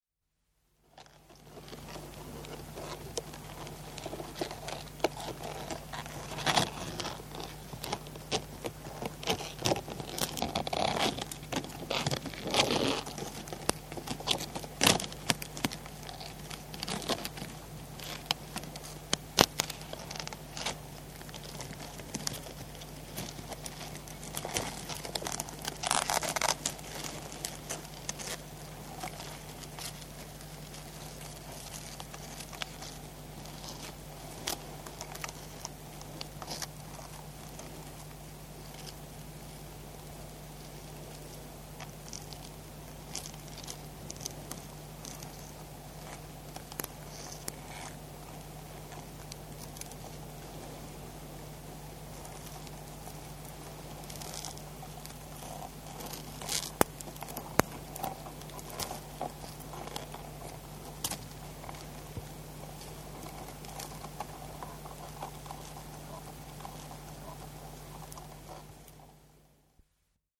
Звук лобстера царапающего что-то